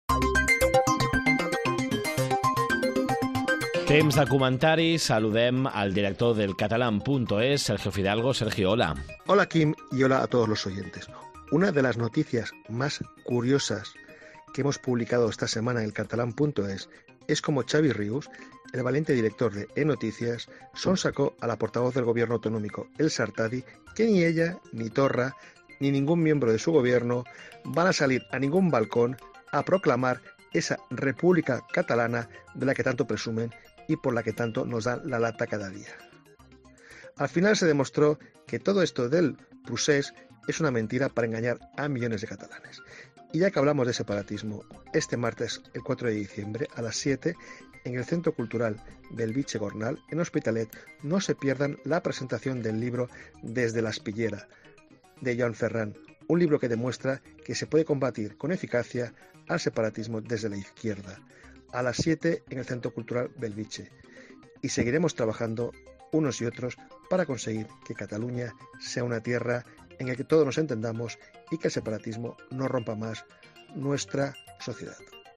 Comentari